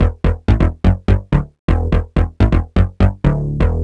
cch_bass_loop_walker_125_D.wav